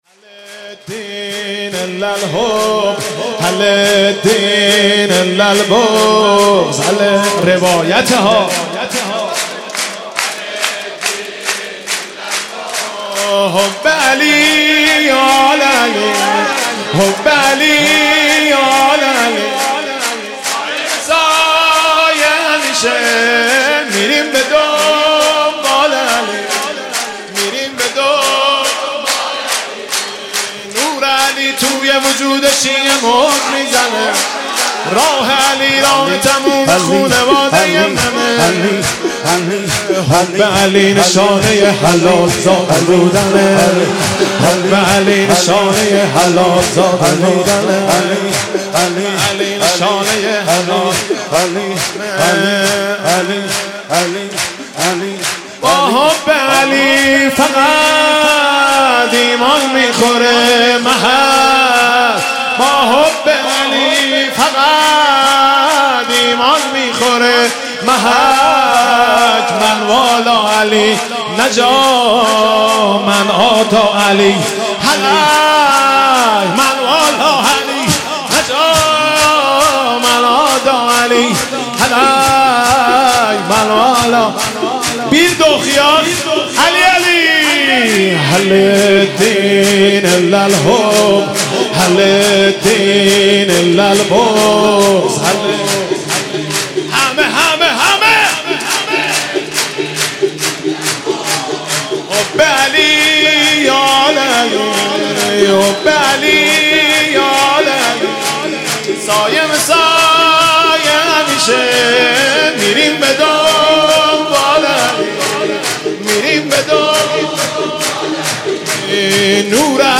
مذهبی
به مناسبت روز پدر – میلاد امام علی علیه السلام